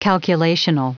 Prononciation du mot calculational en anglais (fichier audio)
Prononciation du mot : calculational